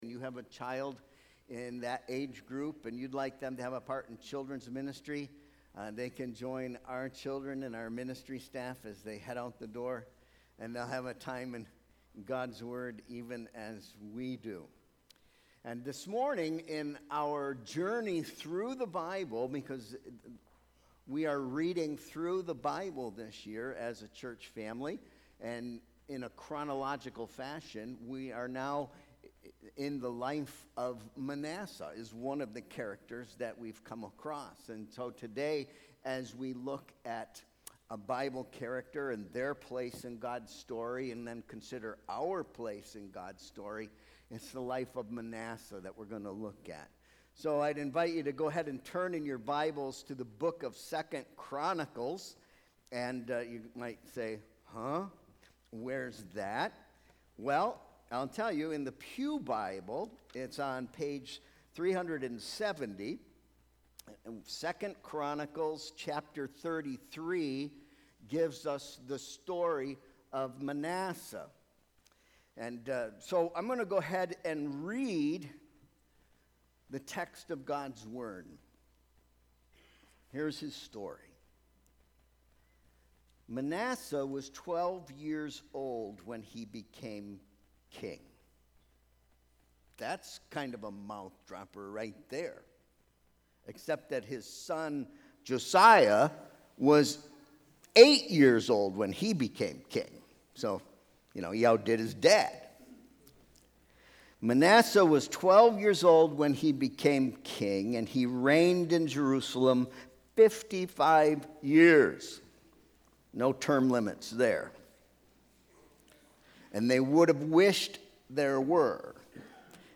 Sermon Proposition: You should take your place in the story of God’s grace for 4 reasons.